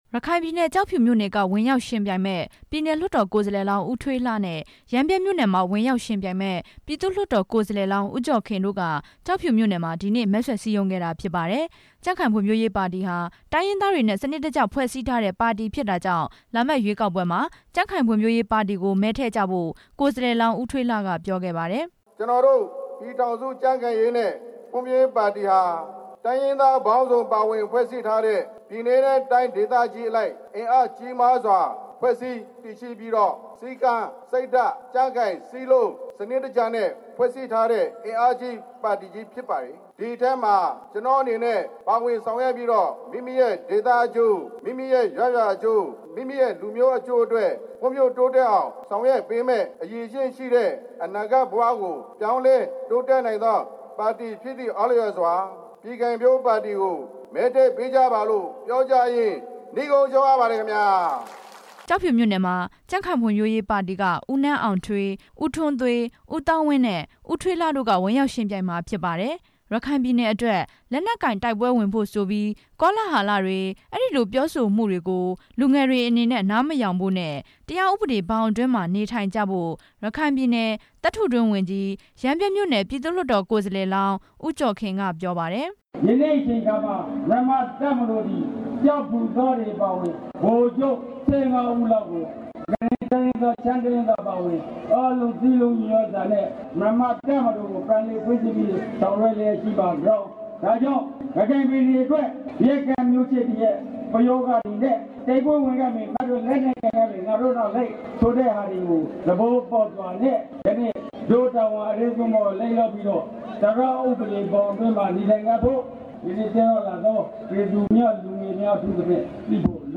ကျောက်ဖြူမြို့ကနေ ပေးပို့ထားပြီး